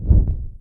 torchoff.wav